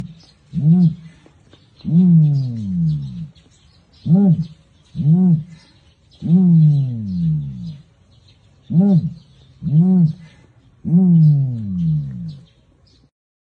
鸵鸟叫声